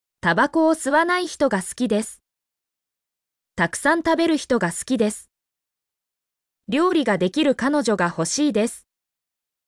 mp3-output-ttsfreedotcom-31_vOplrkQt.mp3